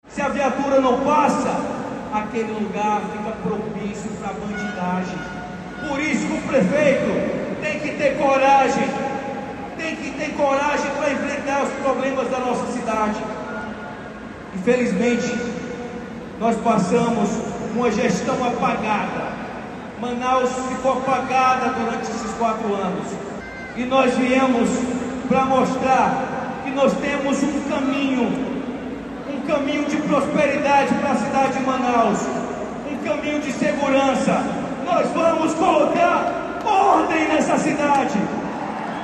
O ex-presidente Jair Bolsonaro participou do Encontro Estadual do PL (Partido Liberal) Amazonas, em Manaus, onde ocorreu o lançamento simbólico da pré-candidatura do deputado federal Alberto Neto à Prefeitura de Manaus pela sigla, em ato que ocorreu nesta sexta-feira, 3, na Arena Poliesportiva Amadeu Teixeira, zona oeste da capital.
Jair Bolsonaro falou após Alberto Neto. No discurso, o ex-presidente citou projetos implementados pelo seu governo em todo o país e disse acreditar no potencial de Alberto Neto nas eleições municipais deste ano: